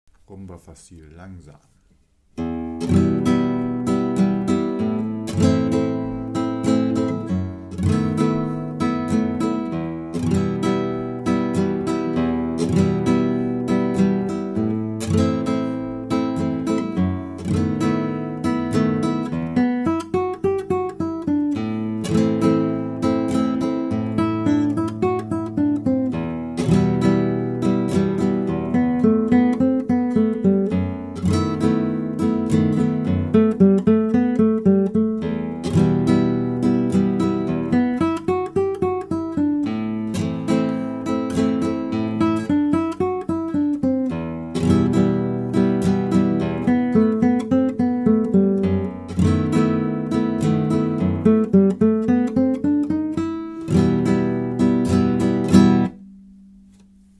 Flamenco Basics
Rumba Facil für spanische Gitarre..
Diese Spieltechnik heißt im Flamenco "Picado"...
langsam
rumbafacil_slow.mp3